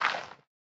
Minecraft Version Minecraft Version snapshot Latest Release | Latest Snapshot snapshot / assets / minecraft / sounds / block / composter / fill1.ogg Compare With Compare With Latest Release | Latest Snapshot